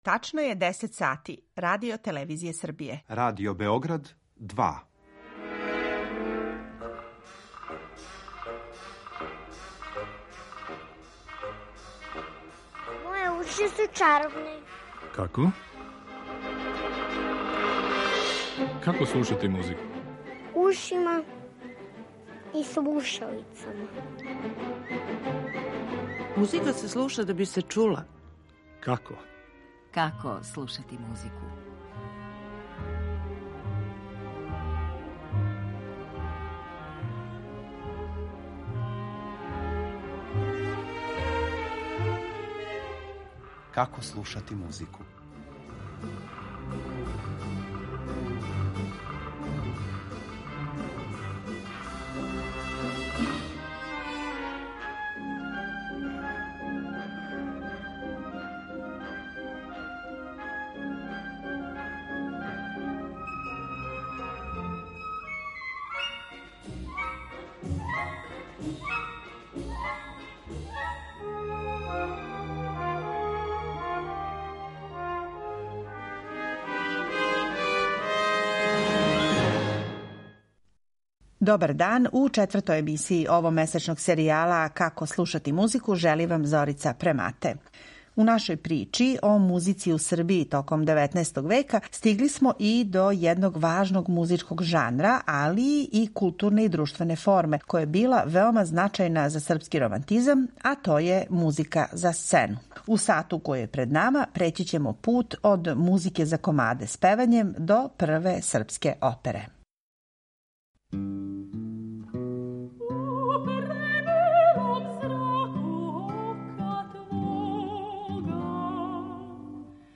У овомесечном циклусу музичких емисија недељом пре подне приказујемо српску музику 19. века: како је компонована, извођена и слушана у Србији и крајевима насељеним Србима у околним државама.